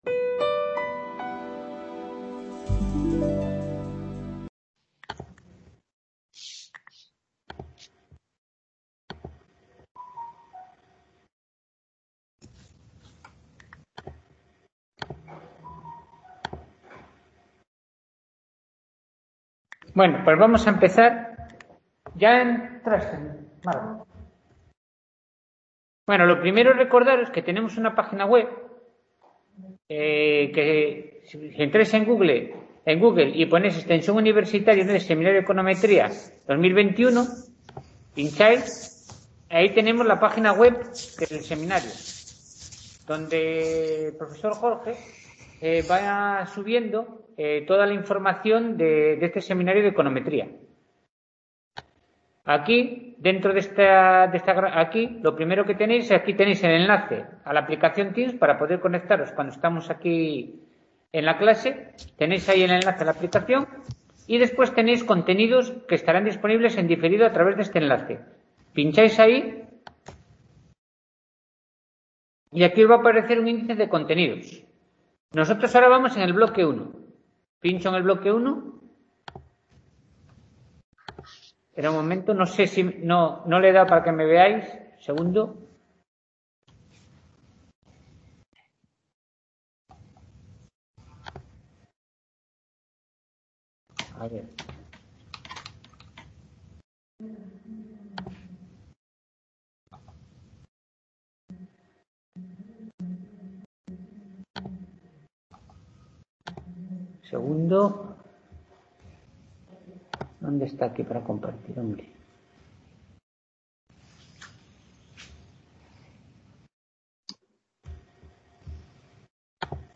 Sesión práctica del Seminario de Econometría Aplicada desarrollada el día 23 de febrero de 2021. Introducción a la Regresión Lineal Múltiple.